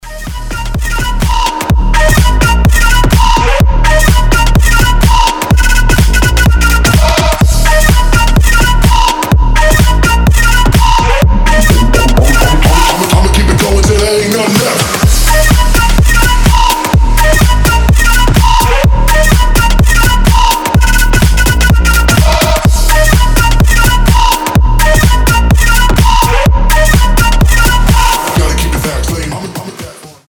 • Качество: 320, Stereo
громкие
жесткие
мощные
EDM
future house
восточные
взрывные
Флейта
electro house
Крутая хаусятина с флейтой